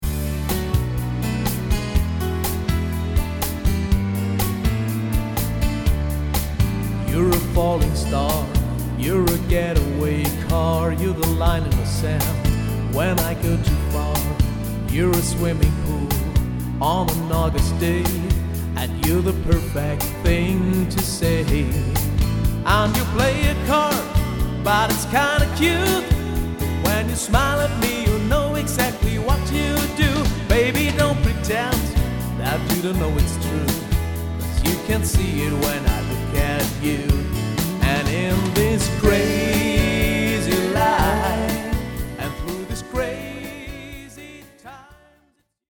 Sängerin, Sänger/Keyboard, Sänger/Schlagzeug